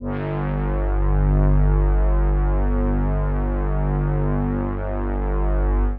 G1_trance_pad_1.wav